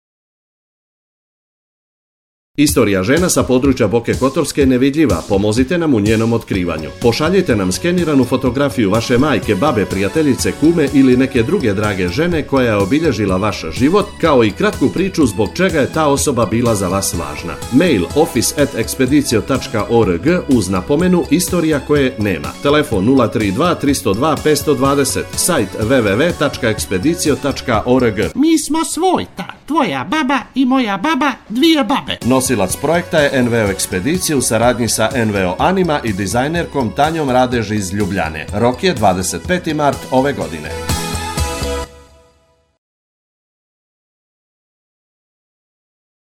Na Lokalnom javnom servisu - Radio Kotor, emituje se